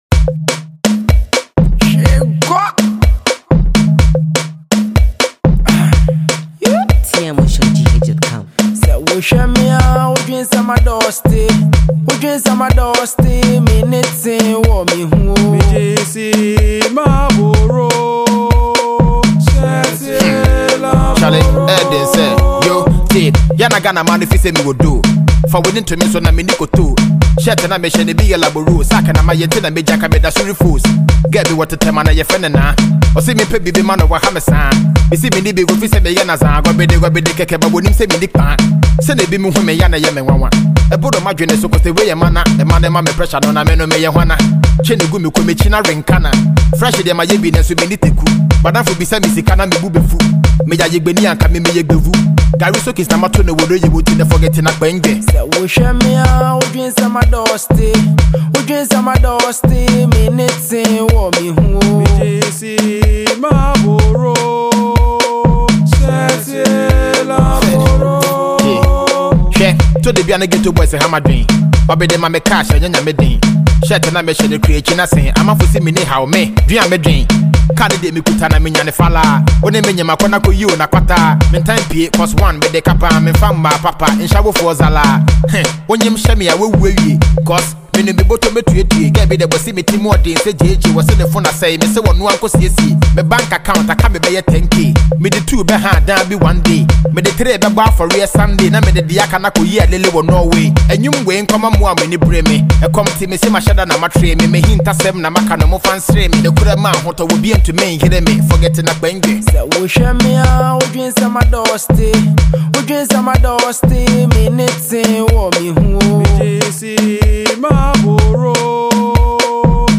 hard rap flows